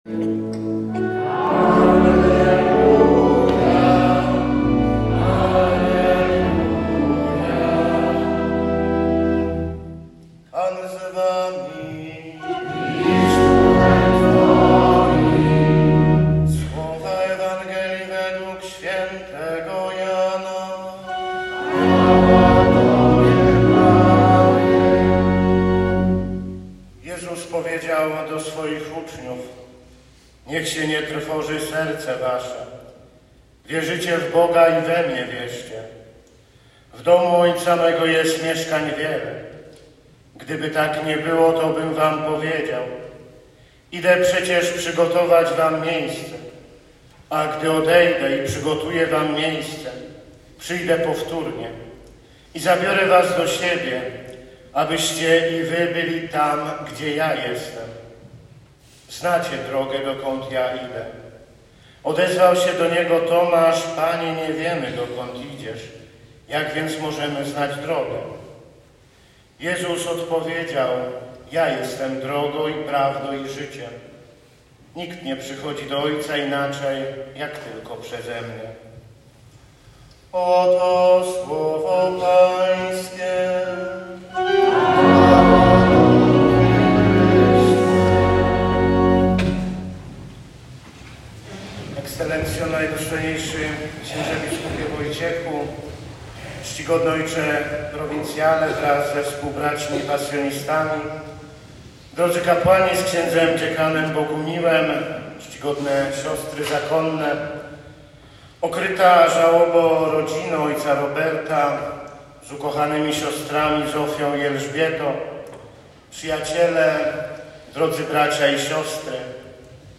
Uroczystości pogrzebowe